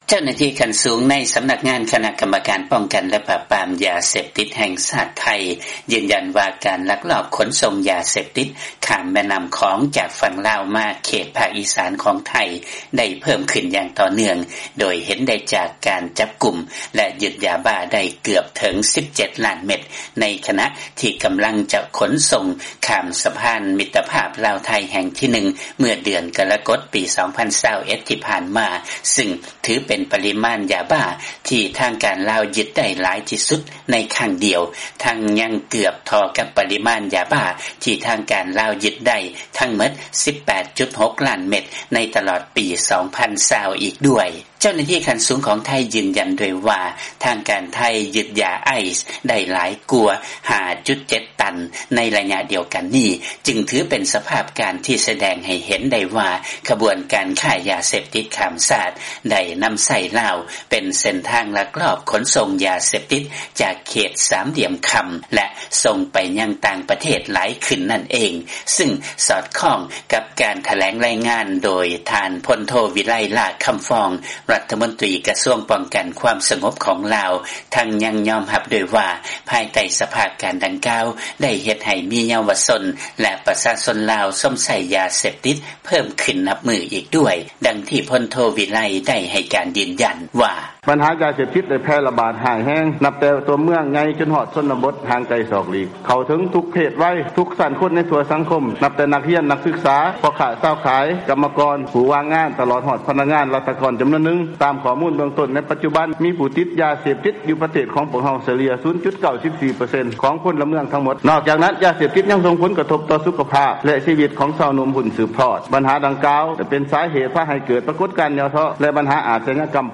ມີລາຍງານ ຈາກບາງກອກ.